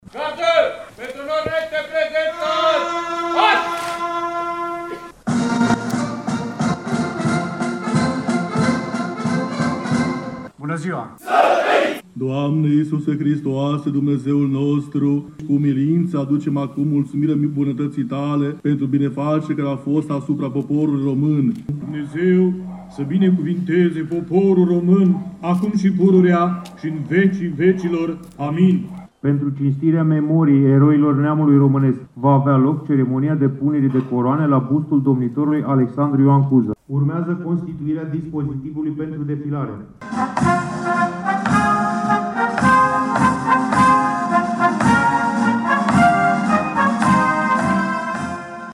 Slujba religioasă